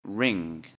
ring.mp3